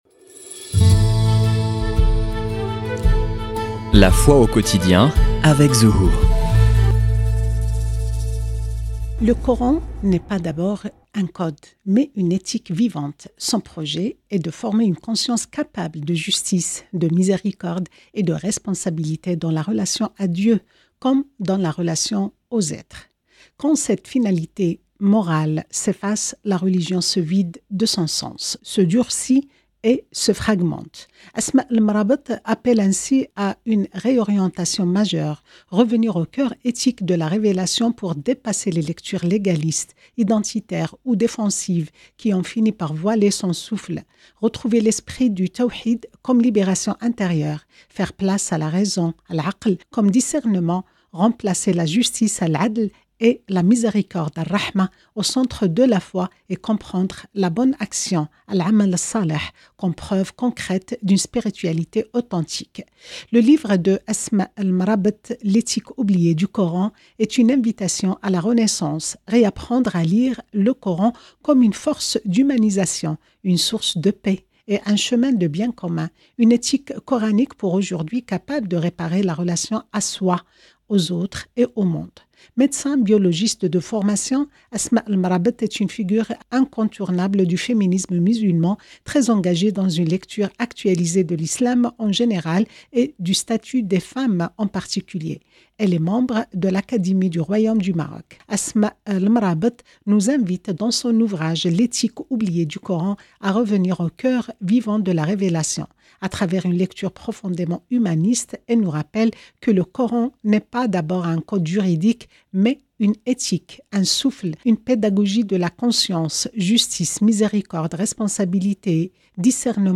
Dans cet épisode De Paris avec vous, on reçoit Dr. Asma Lamrabet, médecin biologiste et figure majeure du féminisme musulman. À travers une lecture humaniste et renouvelée du Coran, elle explore une spiritualité centrée sur la justice, la miséricorde et la responsabilité.